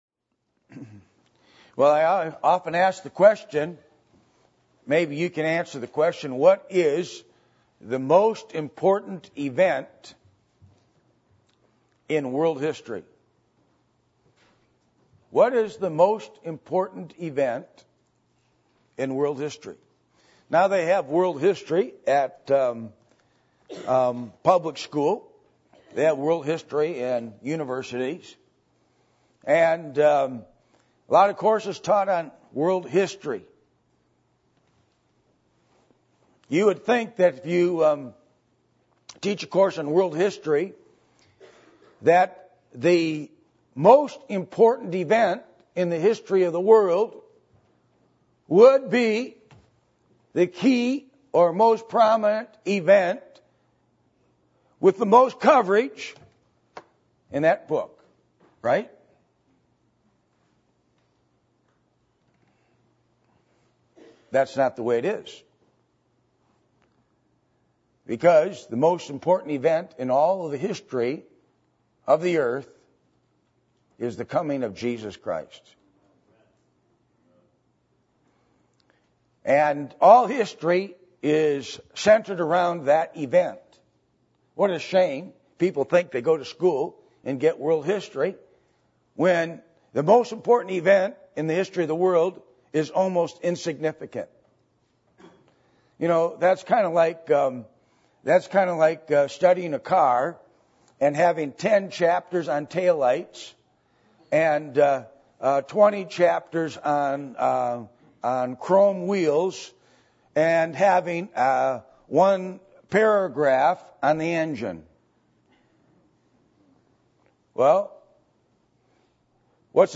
Passage: Zechariah 14:1-21, Revelation 19:1-21 Service Type: Sunday Morning